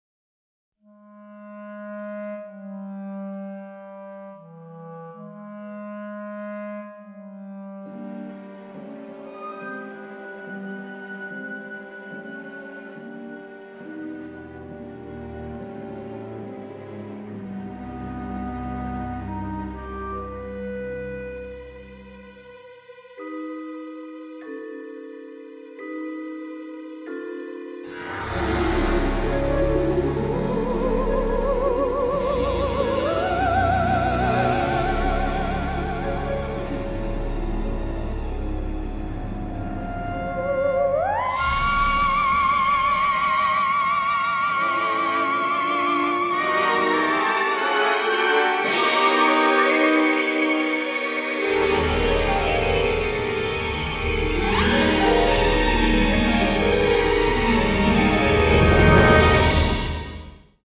create the music using digital samplers